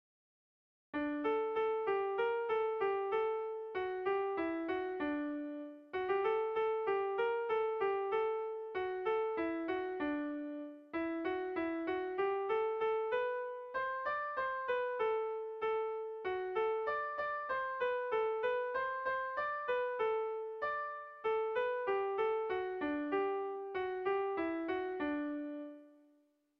Irrizkoa
Sara < Lapurdi Garaia < Lapurdi < Euskal Herria
Hamarreko txikia (hg) / Bost puntuko txikia (ip)
AABDE